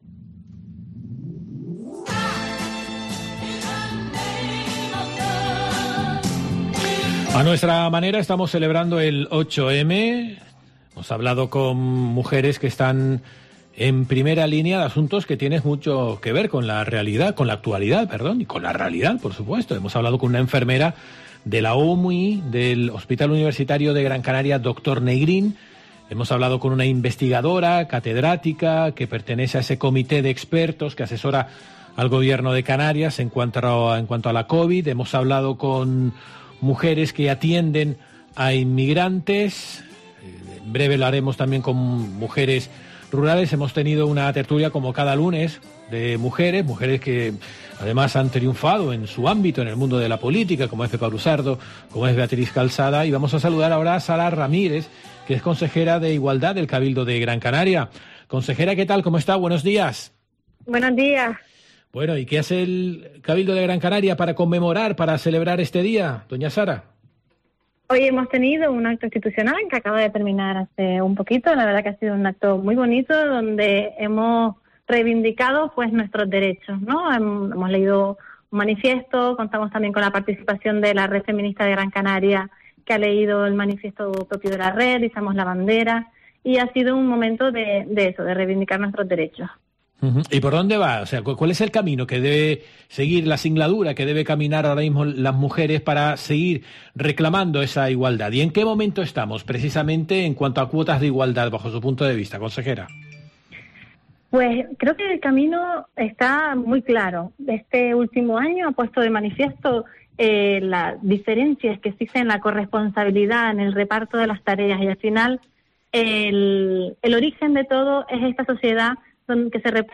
AUDIO: Entrevista con Sara Ramírez, consejera de Igualdad, Diversidad y Transparencia del cabildo de Gran Canaria